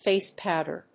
fáce pòwder